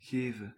Ääntäminen
IPA: /ˈɣeː.və(n)/